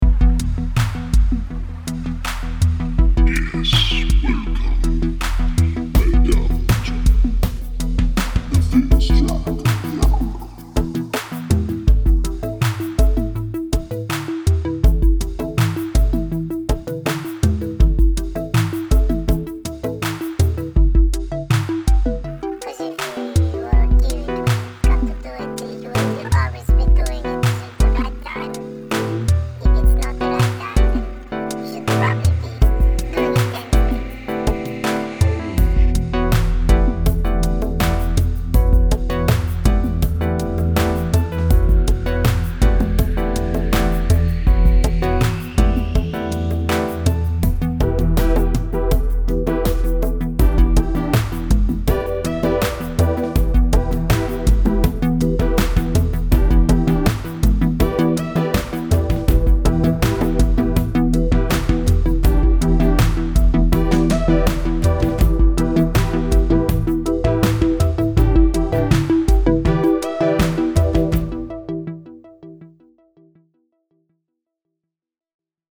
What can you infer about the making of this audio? These original tracks have been created using real instruments, midi composition, and recorded sound.